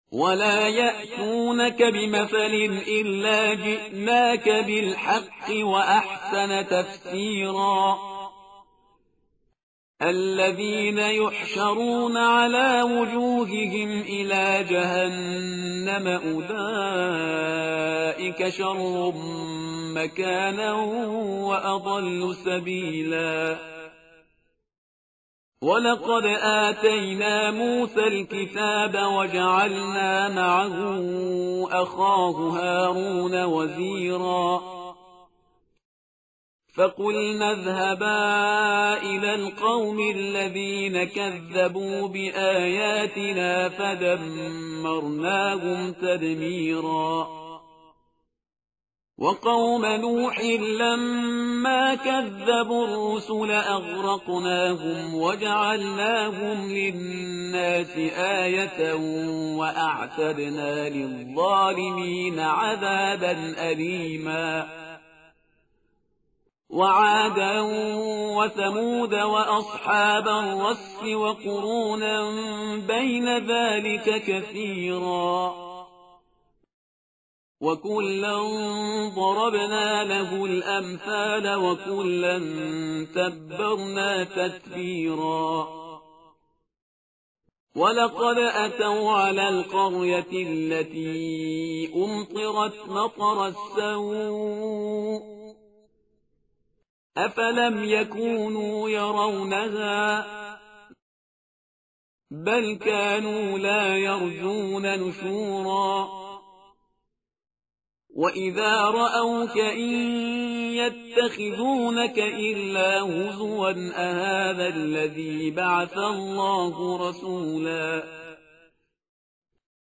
متن قرآن همراه باتلاوت قرآن و ترجمه
tartil_parhizgar_page_363.mp3